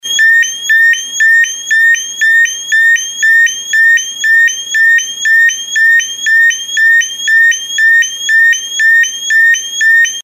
Mini Sirene 2 tons 12V
• Mini sirene piezoelétrica 12V
• 2 tom, 120dB
Mini sirene piezoelétrica 12V, 2 tons 120 dB de uso geral, com opção diurno/noturno.
Nesta situação a intensidade sonora será a metade do normal.